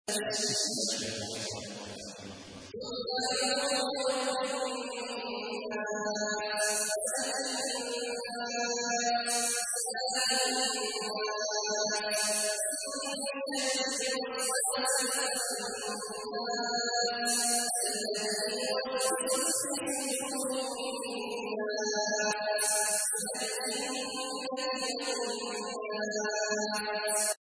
تحميل : 114. سورة الناس / القارئ عبد الله عواد الجهني / القرآن الكريم / موقع يا حسين